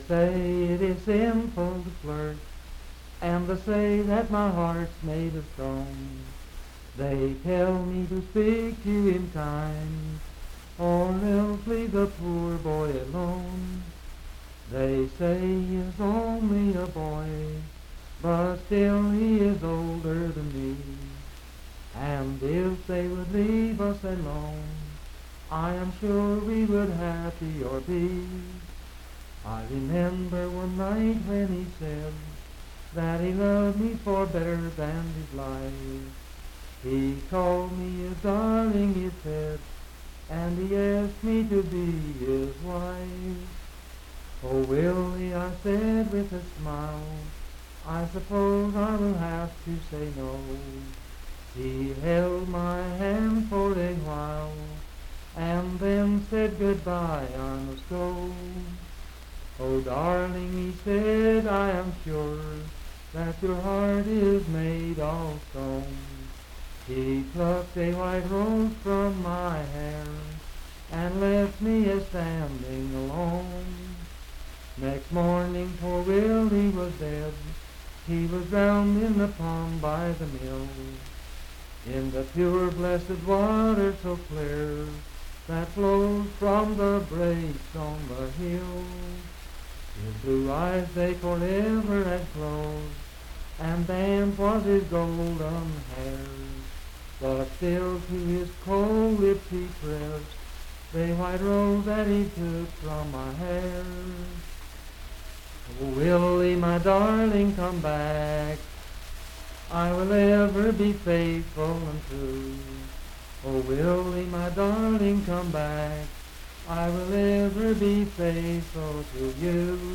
Unaccompanied vocal music
Voice (sung)
Pocahontas County (W. Va.), Marlinton (W. Va.)